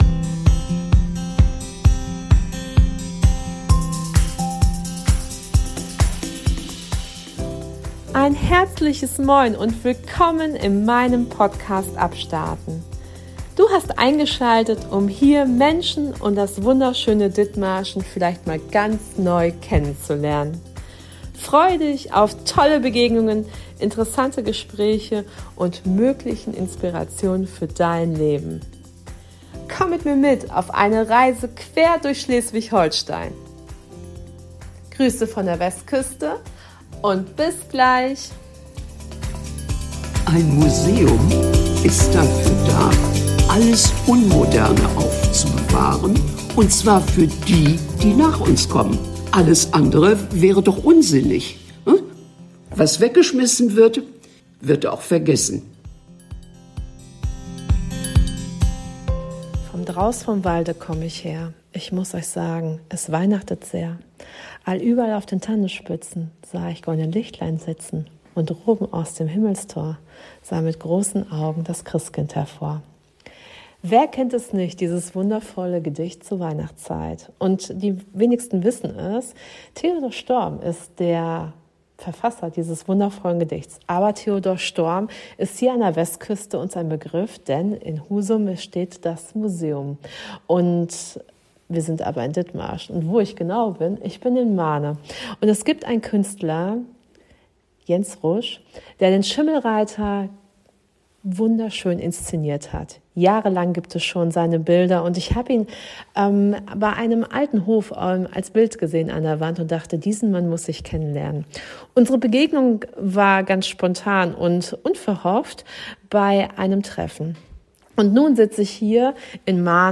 Beschreibung vor 1 Jahr Ort: Musuem - Skatclub Marne Uhrzeit Mittags rum Anwesende